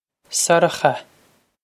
Sorcha Sur-uh-khuh
Pronunciation for how to say
Sur-uh-khuh
This is an approximate phonetic pronunciation of the phrase.